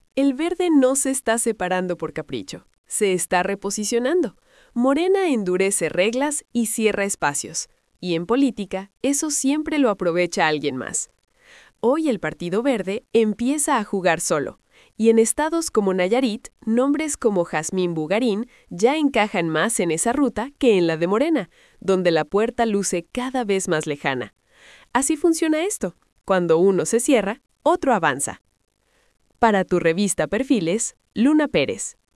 🎙 COMENTARIO EDITORIAL
ASÍ LO DIJO LA PRESIDENTA DEL PVEM…